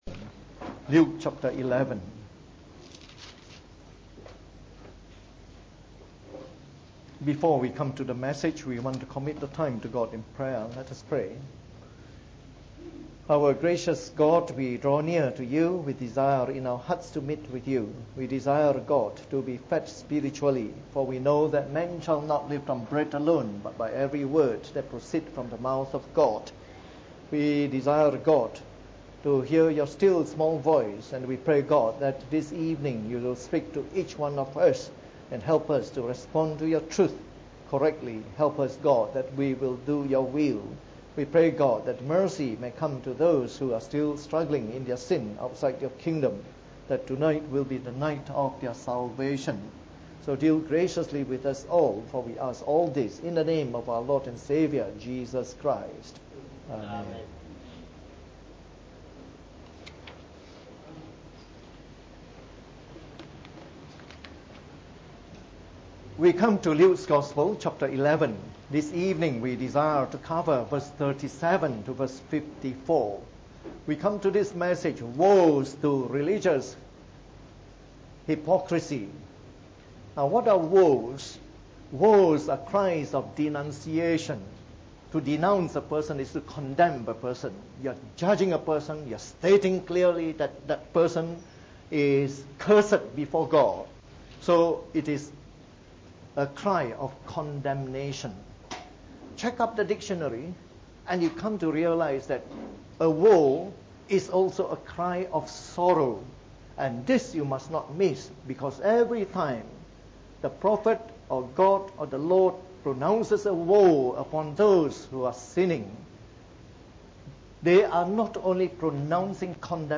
From our series on the “Gospel According to Luke” delivered in the Evening Service.